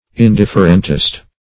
\In*dif"fer*ent*ist\